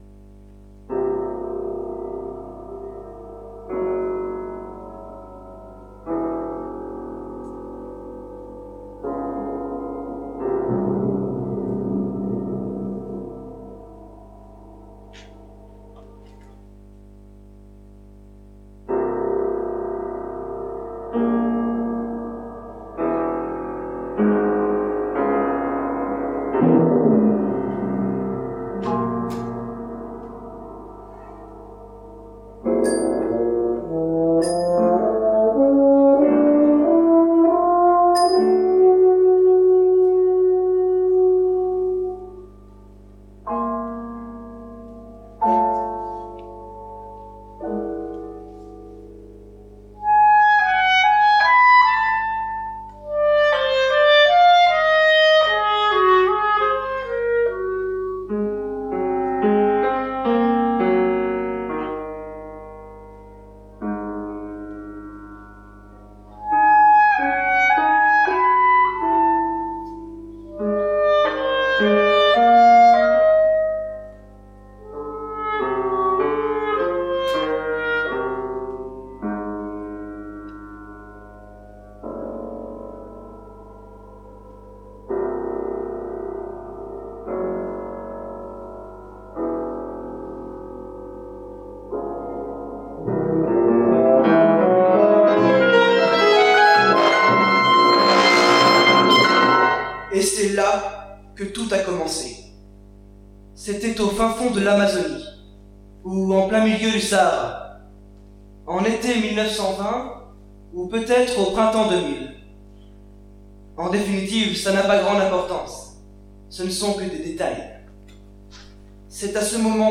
La première representation